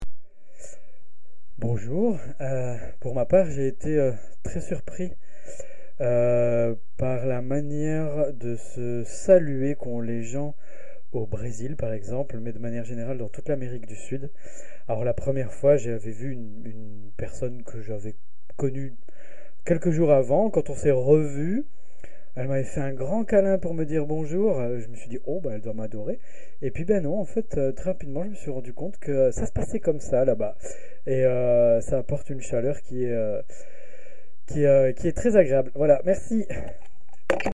Cabine de témoignages